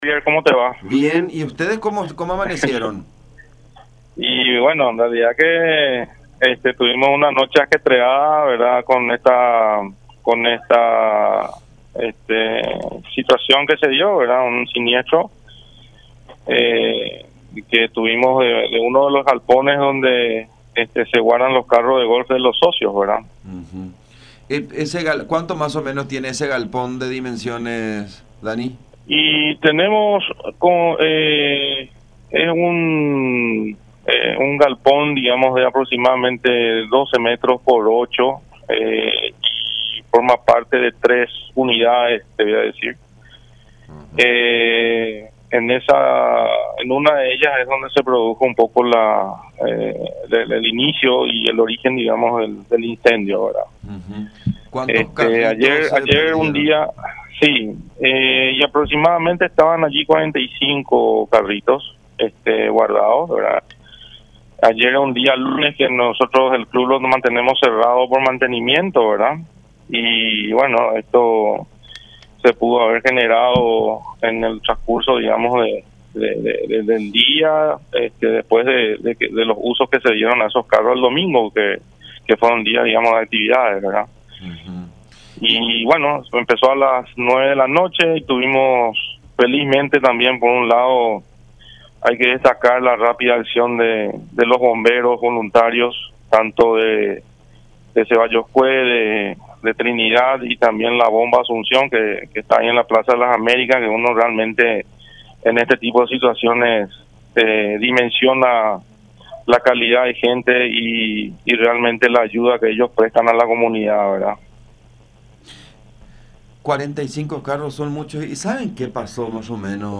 en conversación con La Unión.